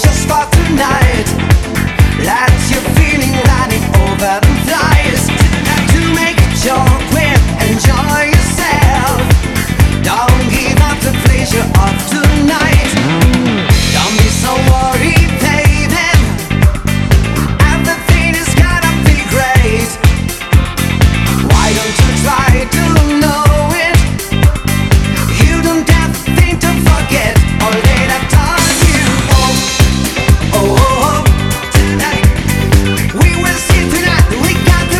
Жанр: Музыка мира